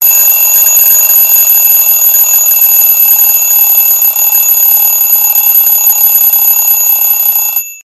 Categoria Allarmi